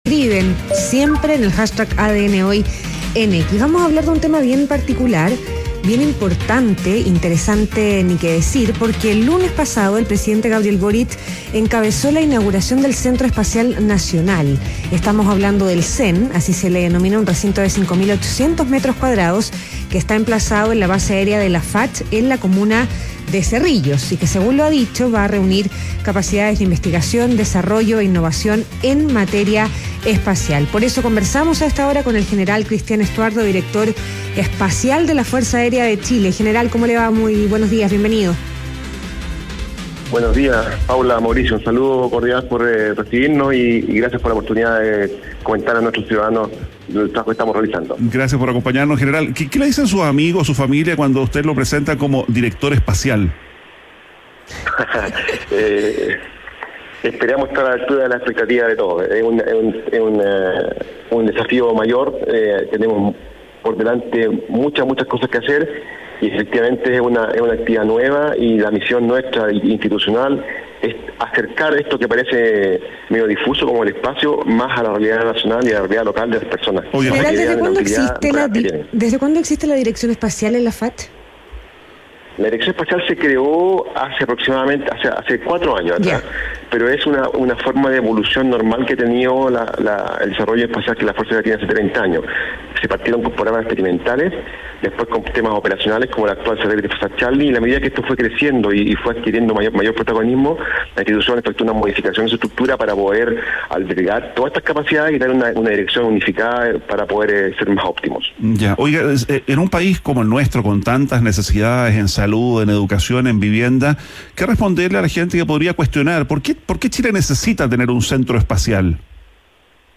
ADN Hoy - Entrevista al general Christian Stuardo, director espacial de la Fuerza Aérea de Chile